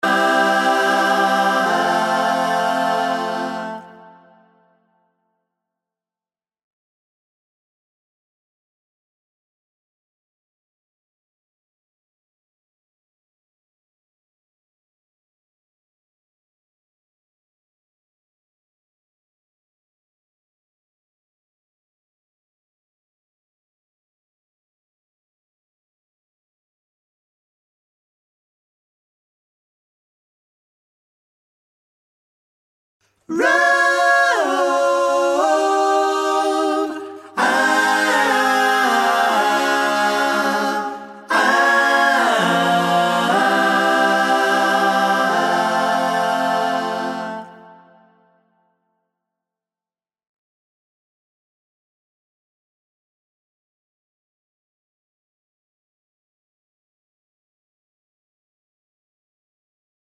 BV Stem With FX Pop (1970s) 3:14 Buy £1.50